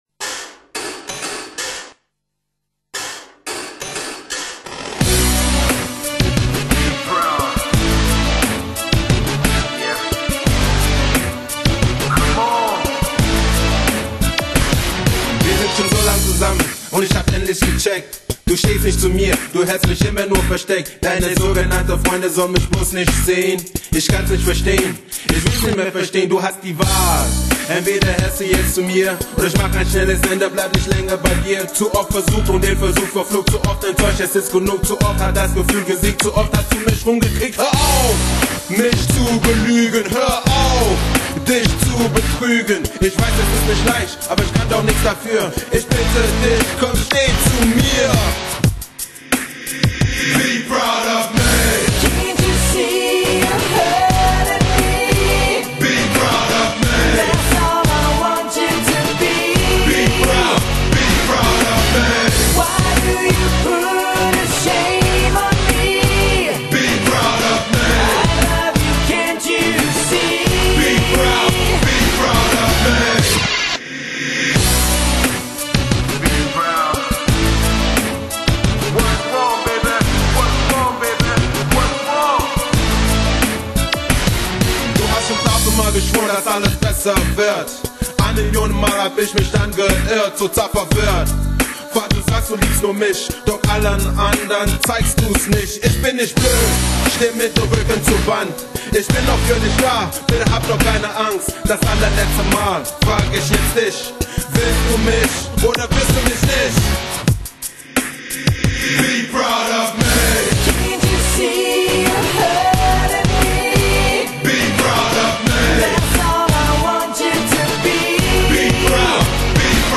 Rock-Mix